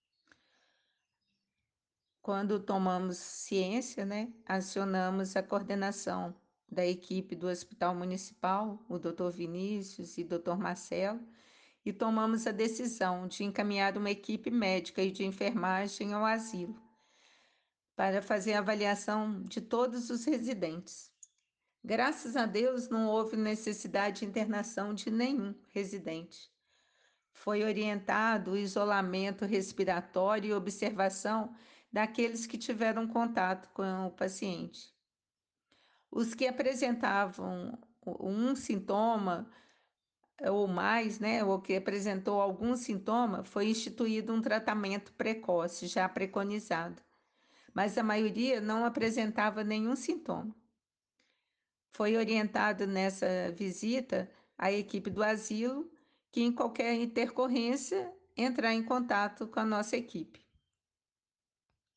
Ouça a entrevista da secretária Municipal de Saúde